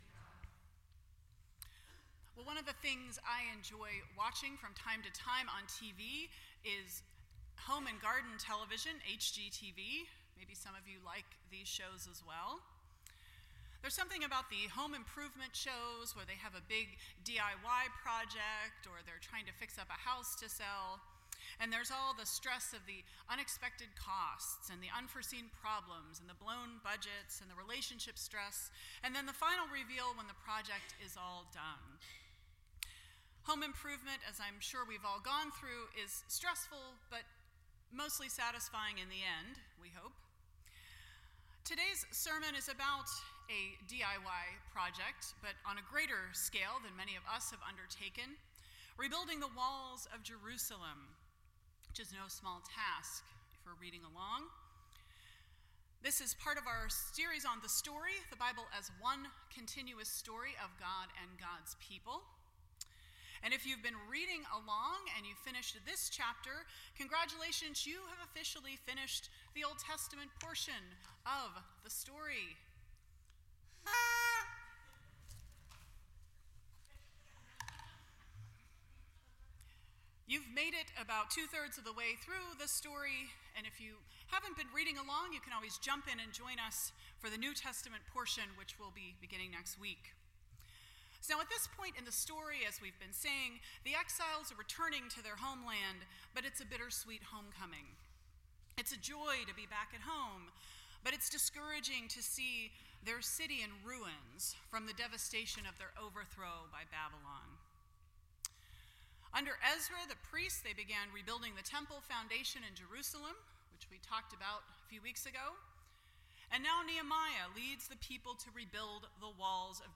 The Story Service Type: Sunday Morning %todo_render% Share This Story